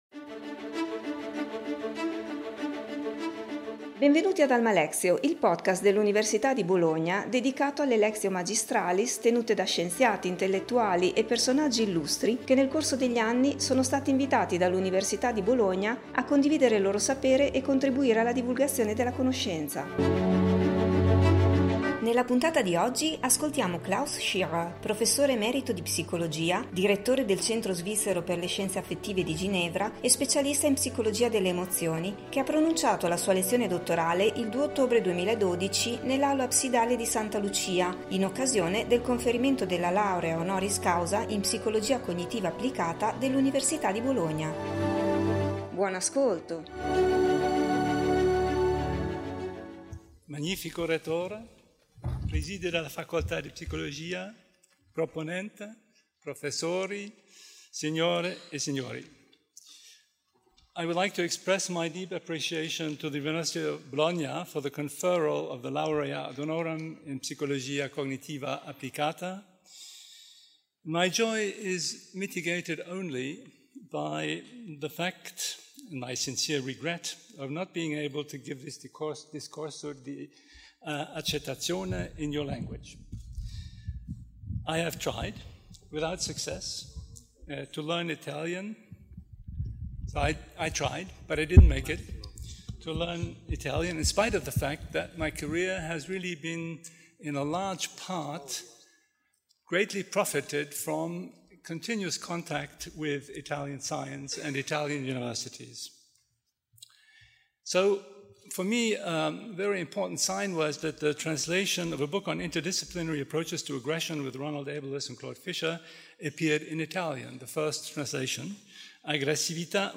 Klaus Rainer Scherer, professore emerito di psicologia e direttore del Centro Svizzero per le Scienze Affettive di Ginevra, nonché specialista in psicologia delle emozioni, ha pronunciato la sua lezione dottorale il 2 ottobre 2012 nell’Aula Absidale di Santa Lucia in occasione del conferimento della Laurea honoris causa in Psicologia Cognitiva Applicata dell’Università di Bologna.